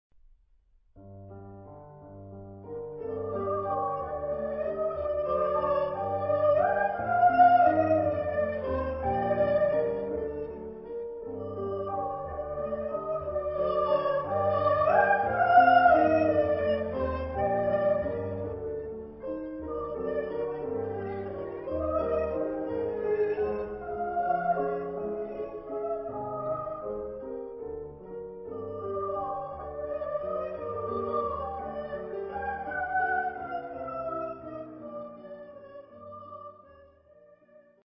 S (1 voices unison) ; Full score.
Romantic. Waltz.
sung by Kölner Kammerchor conducted by Peter Neumann